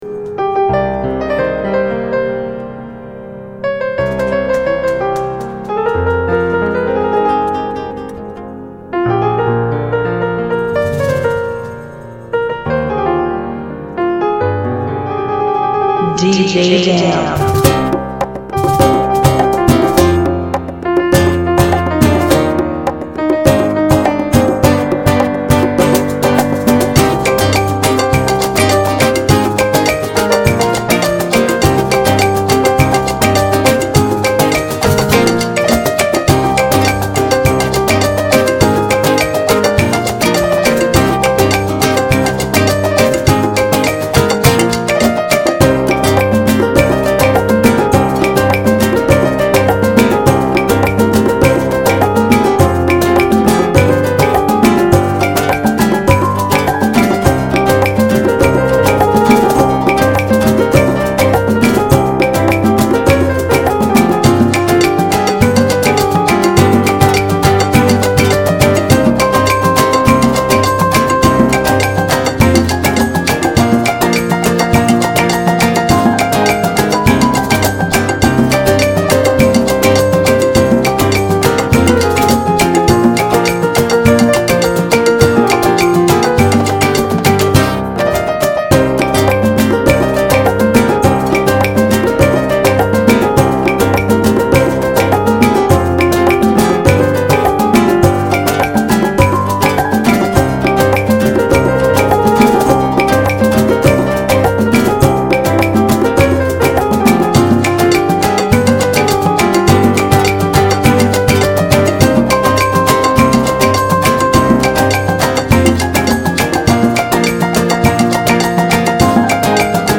Salsa Remix